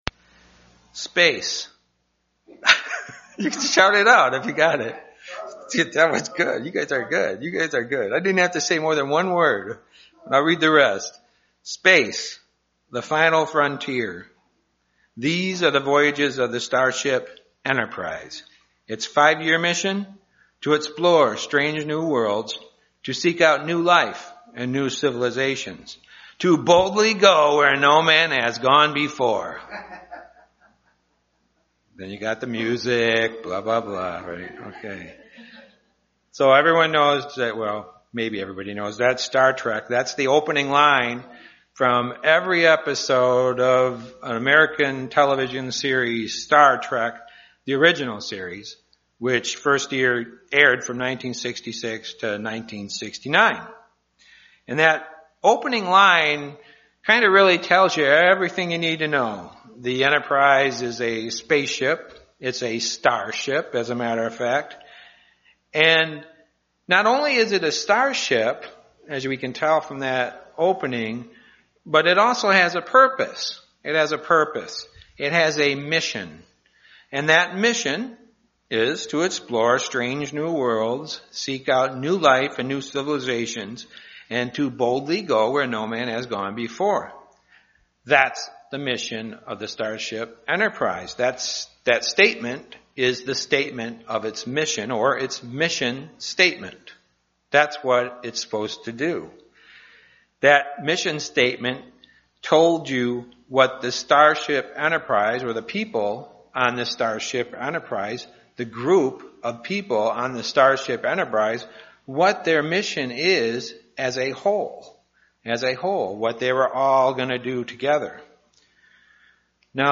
Given in Grand Rapids, MI
We each have a purpose and a gift to do a specific job in God's master plan UCG Sermon Studying the bible?